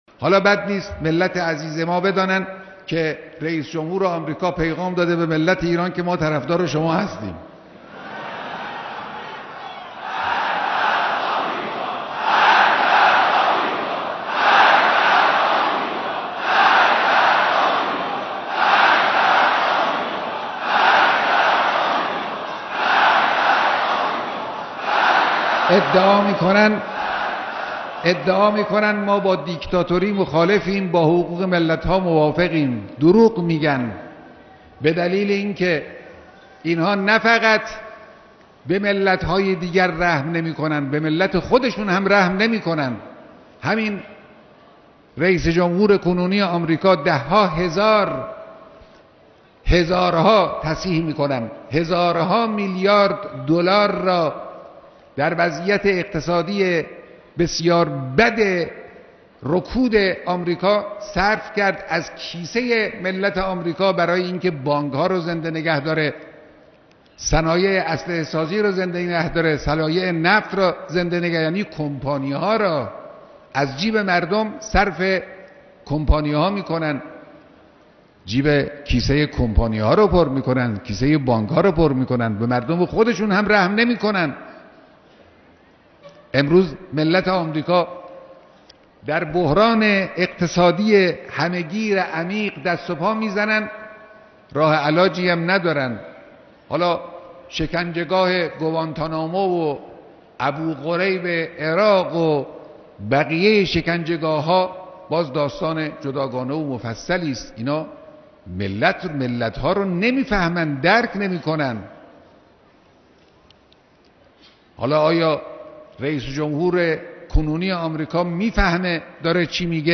مقتطفات من كلمة الإمام الخامنئي في لقاء مع جمع من الرعيل الأول لقادة ومجاهدي «الدفاع المقدس»
ہفتۂ مقدس دفاع کے موقع پر مقدس دفاع کے کمانڈروں اور سینیئر سپاہیوں سے ملاقات میں تقریر